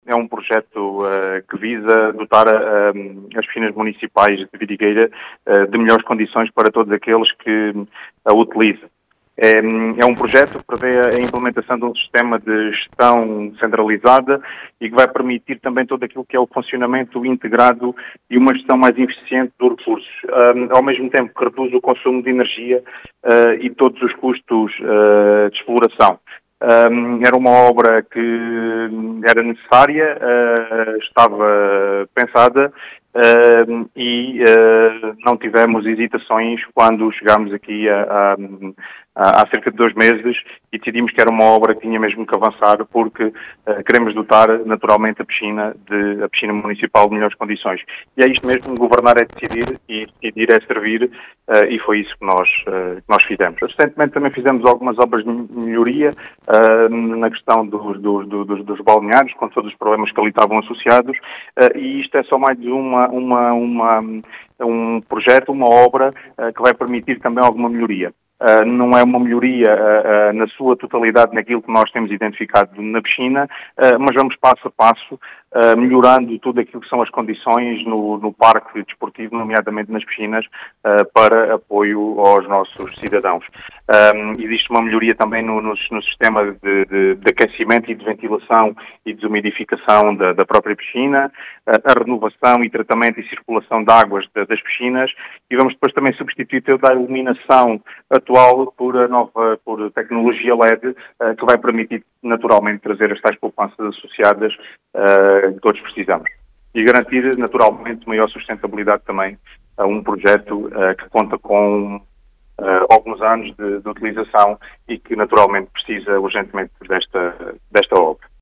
As explicações são do presidente da Câmara Municipal de Vidigueira, Ricardo Bonito, que fala deste projeto.